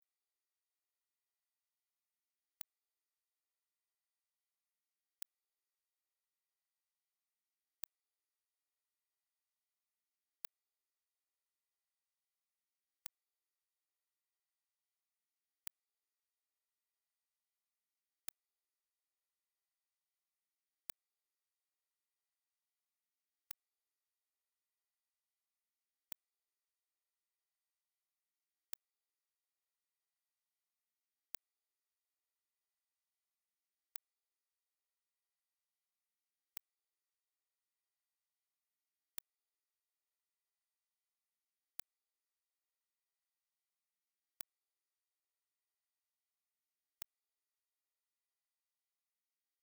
X22 kHz Frequency (18 & Younger can hear)